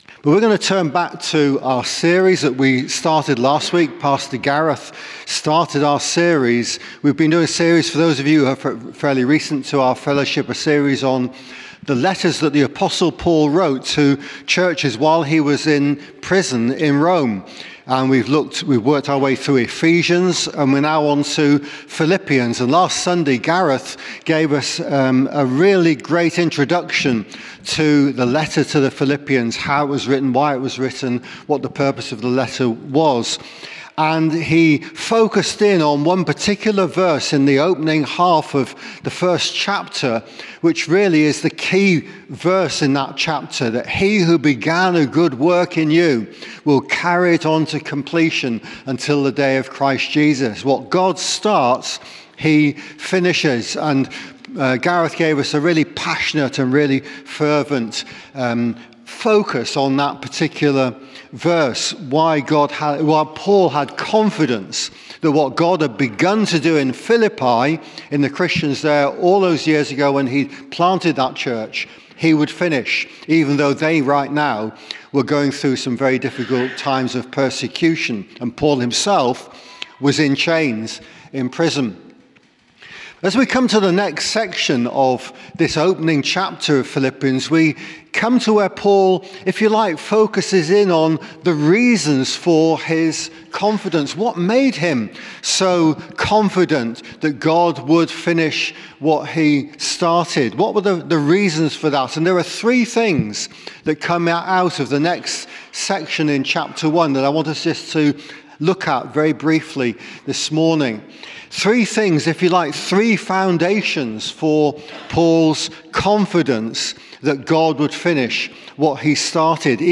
Sermon - 'The Gospel cannot be chained' Philippians 1:12